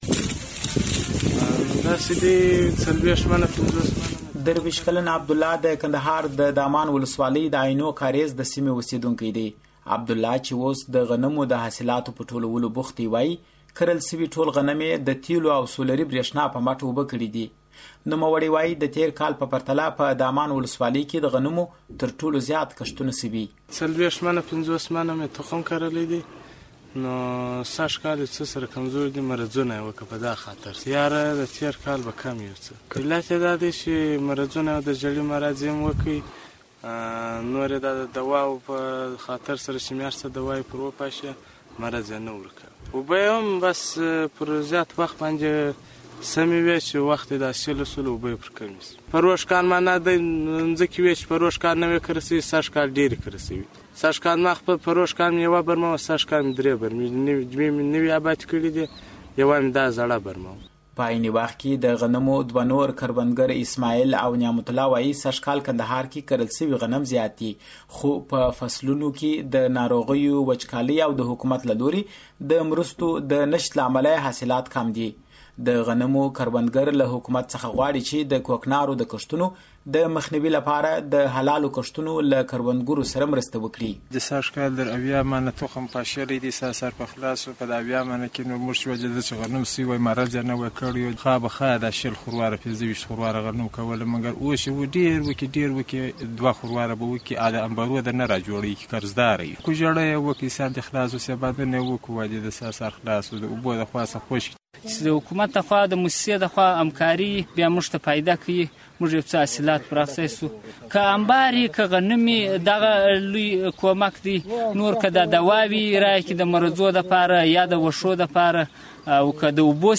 غنمو په اړه د کندهار راپور